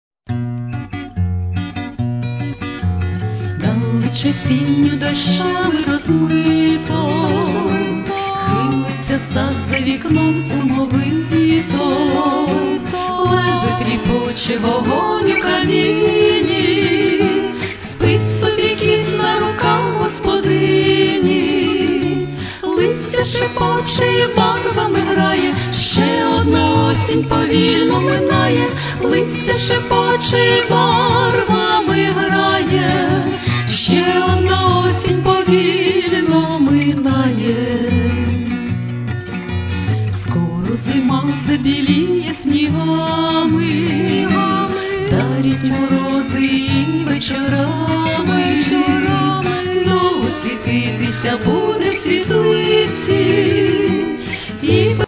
Bards (14)